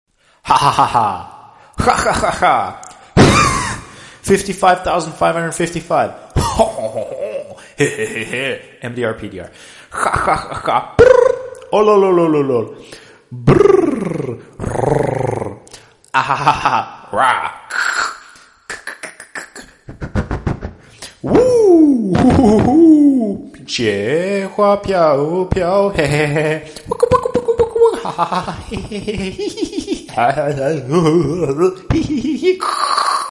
Laughing in different languages ❤